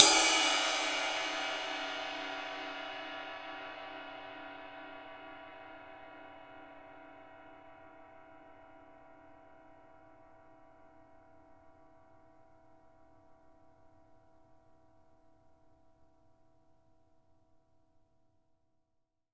Index of /90_sSampleCDs/Sampleheads - New York City Drumworks VOL-1/Partition A/KD RIDES
RIDE2     -L.wav